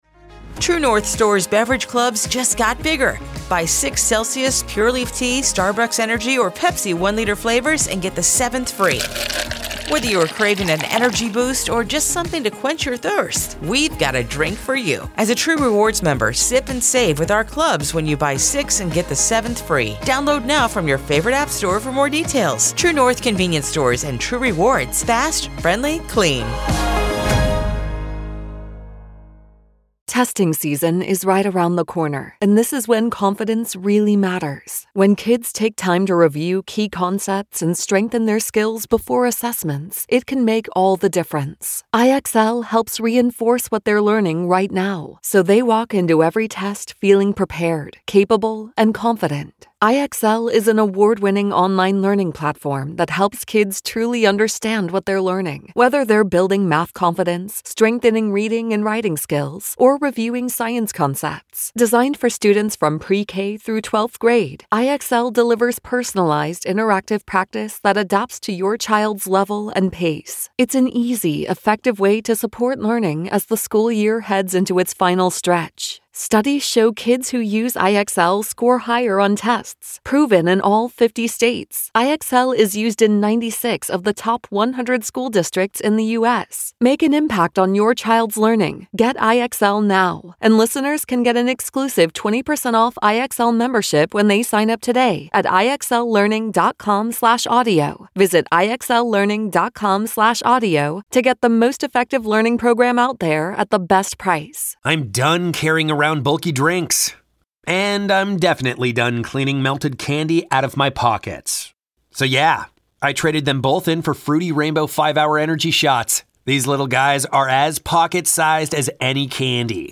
The 94WIP Morning Show delivers everything Philly sports fans crave — passionate takes, smart analysis, and the kind of raw, authentic energy that defines the city.
You can catch the 94WIP Morning Show live on SportsRadio 94WIP weekdays from 6–10 a.m.